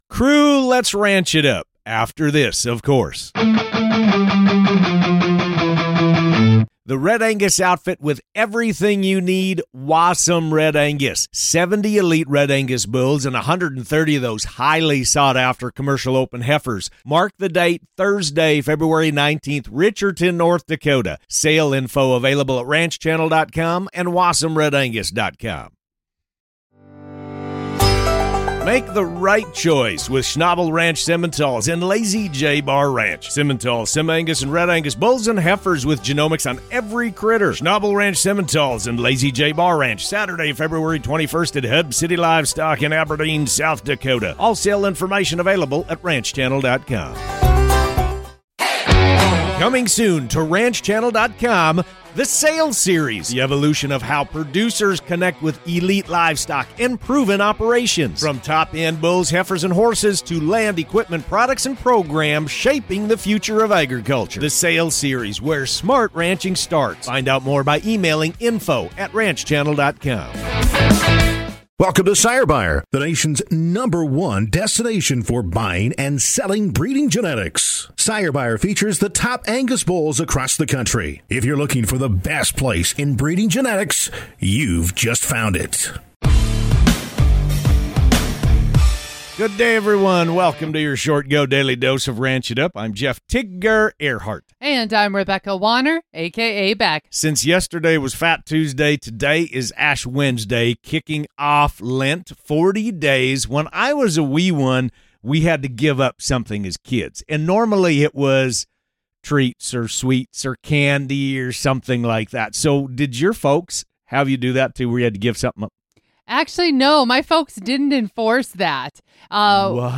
Expect insightful (and hilarious) commentary, listener shout-outs, and everything you need to stay in the loop on all things ranch. It's your daily squeeze of ranchy goodness!